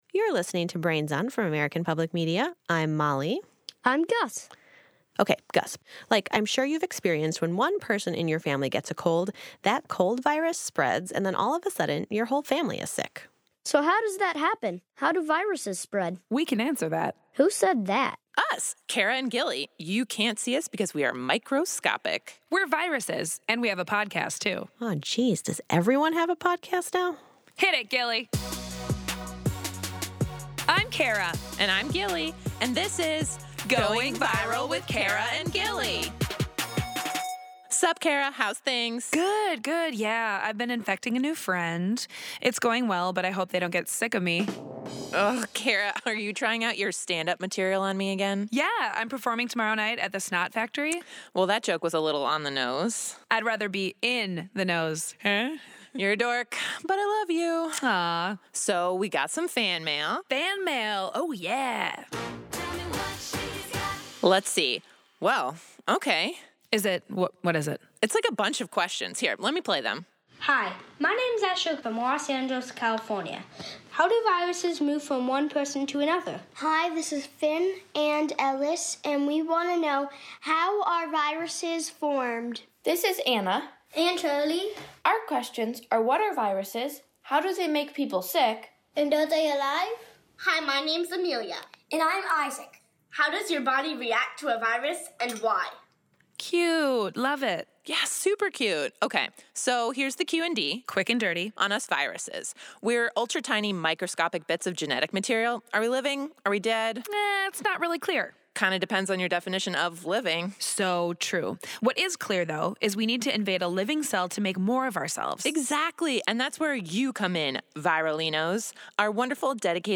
Brains On! uses various strategies to deliver content, including incorporating humor, featuring kids’ voices, answering questions submitted by children, interviewing scientists and other experts, and modeling calmness when talking about scary topics. Listeners frequently mentioned the show’s “Kara and Gilly” sketch—a podcast within a podcast hosted by two viruses—as a feature that stood out for using many of these strategies to convey content.